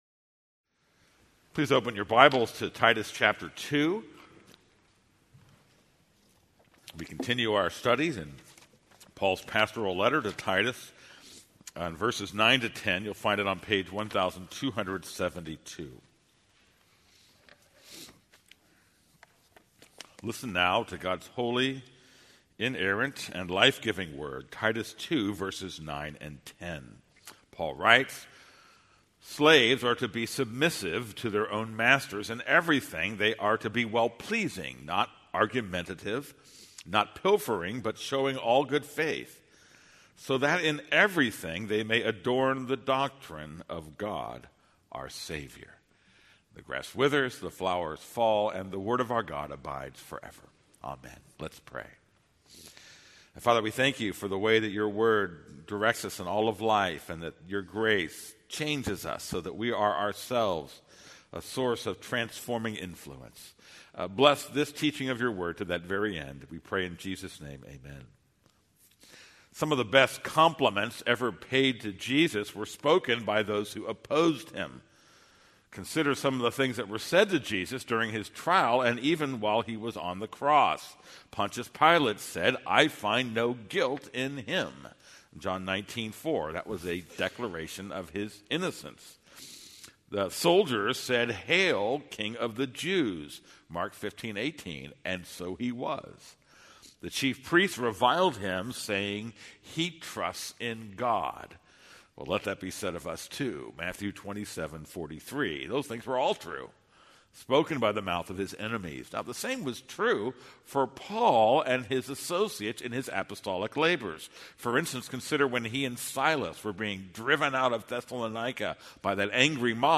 This is a sermon on Titus 2:9-10.